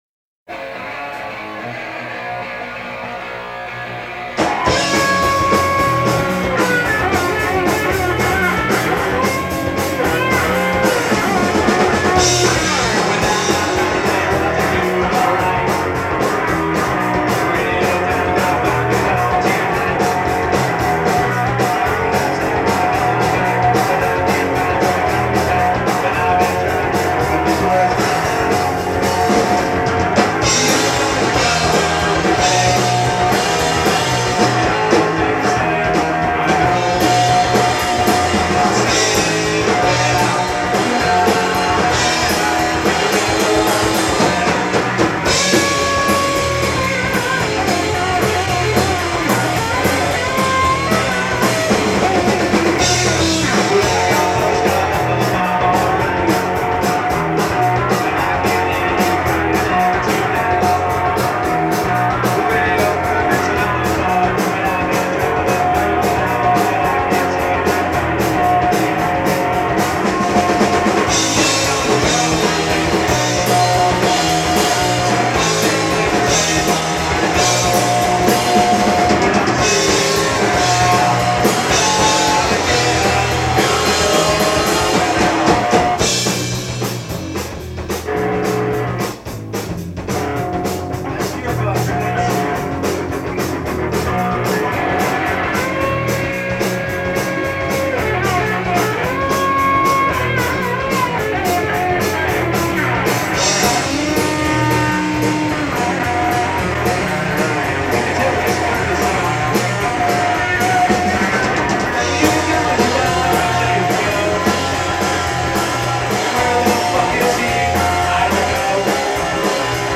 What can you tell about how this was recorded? Live Khyber Pass, Philadelphia 1995